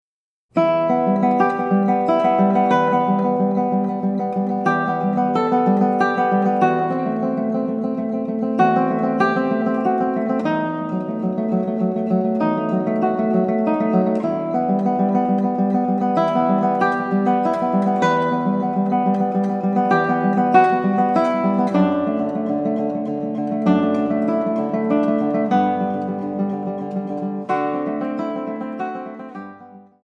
and Spanish favorites for classical guitar.